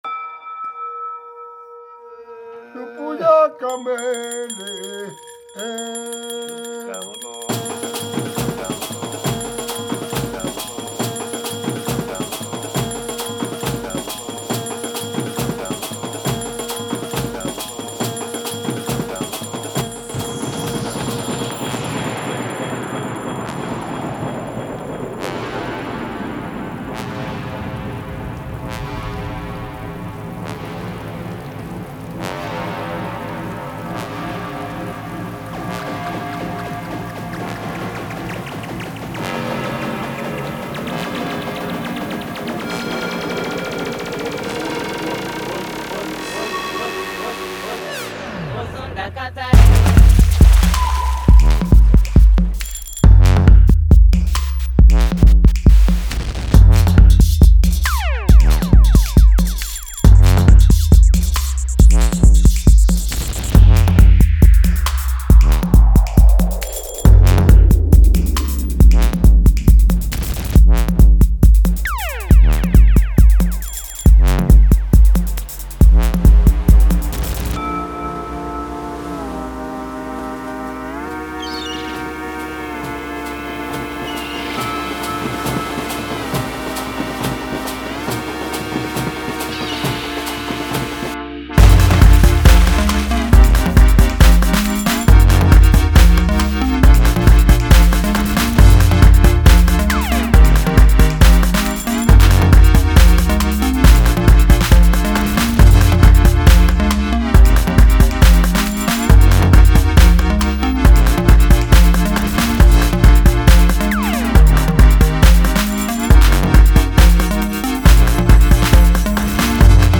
la sinuosa e martellante